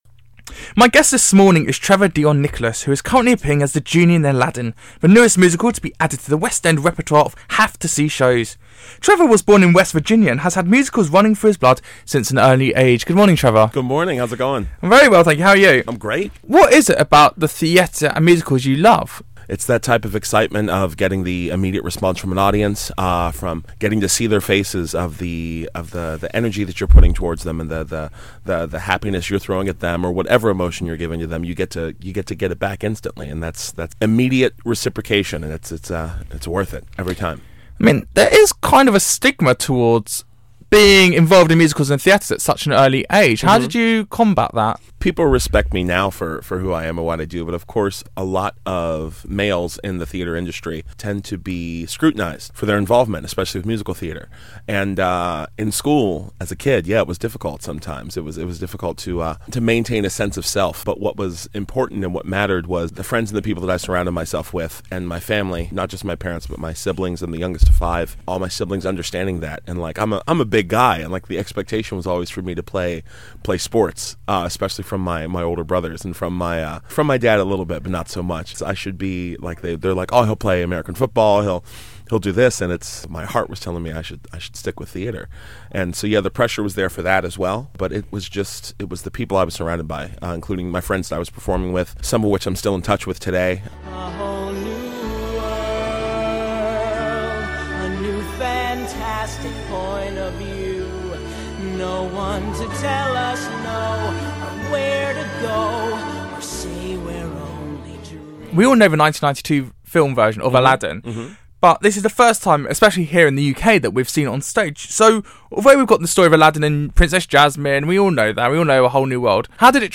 Interview with Genie, Aladdin